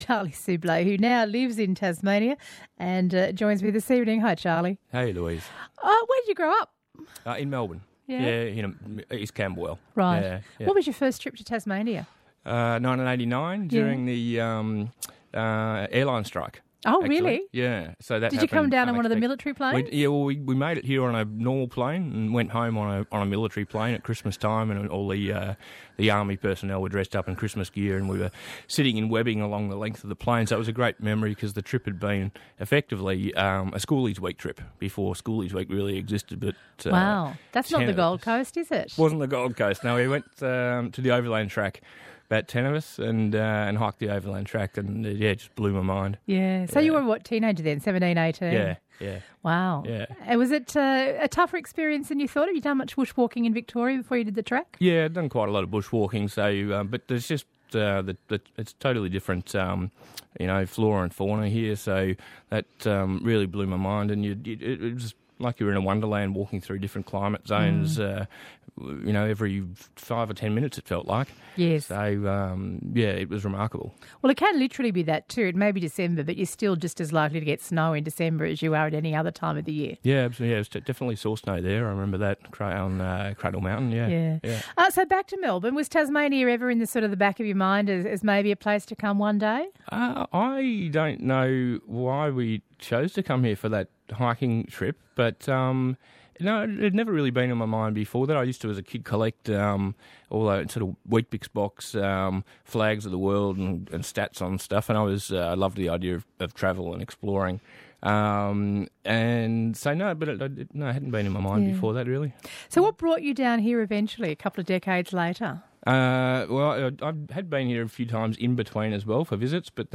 an interview about why I moved to Tasmania